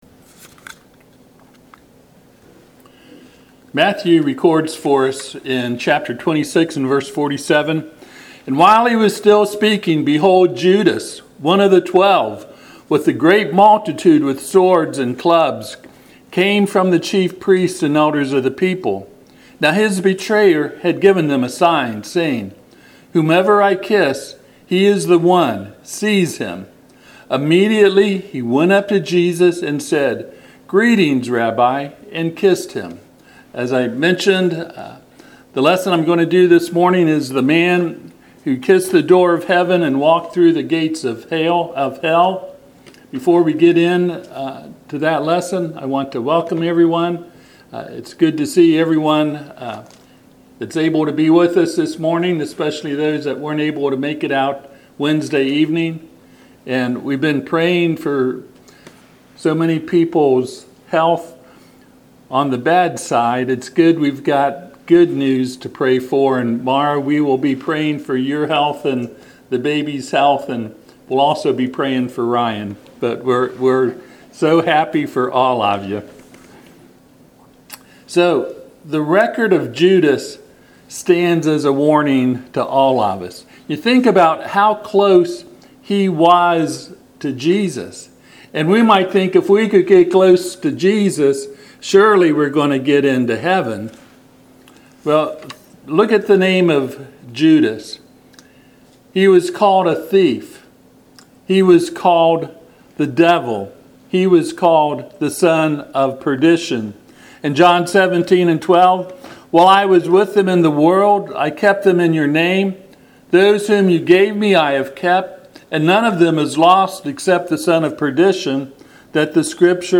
Passage: Matthew 26:47-49 Service Type: Sunday AM